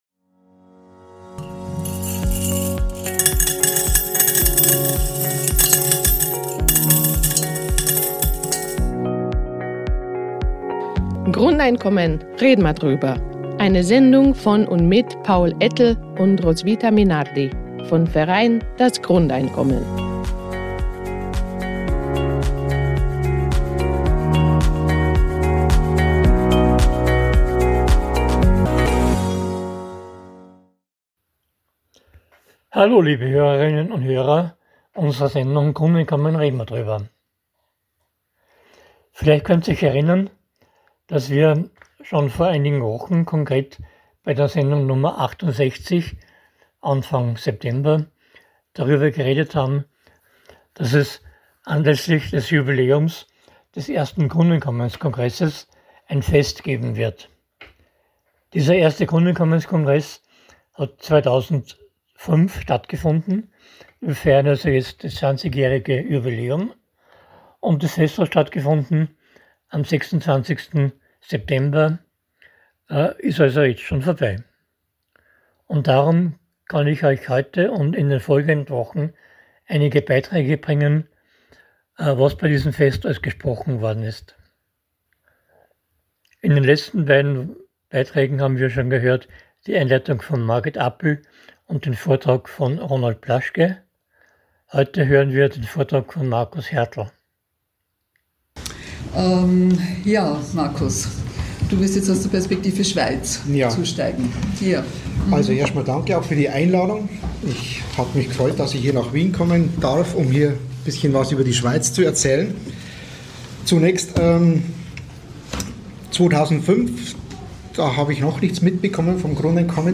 Dies ist der dritte von 5 Beiträgen über das Fest anlässlich des 20-Jahre-Jubiläums des ersten Grundeinkommenskongresses im deutschsprachigen Raum.